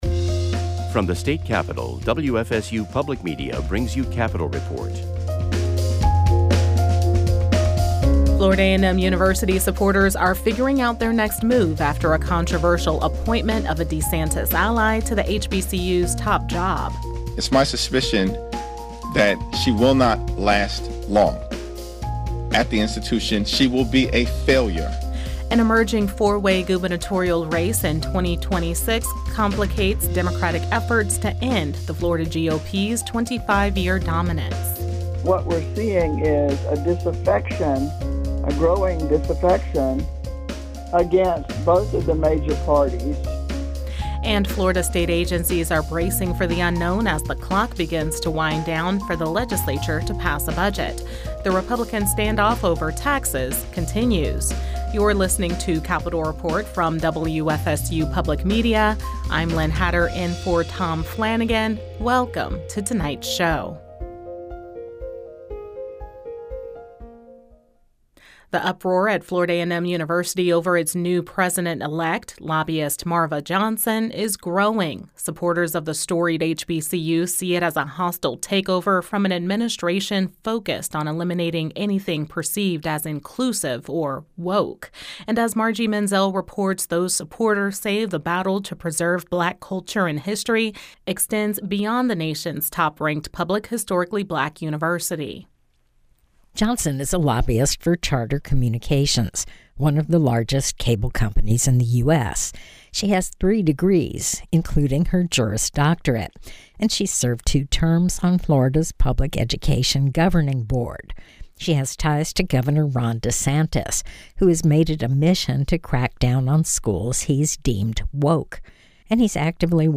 WFSU Public Media reporters, as well as reporters from public radio stations across the state, bring you timely news and information from around Florida. Whether it's legislative maneuvers between sessions, the economy, environmental issues, tourism, business, or the arts, Capital Report provides information on issues that affect the lives of everyday Floridians.